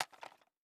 rpg7_endgrab.wav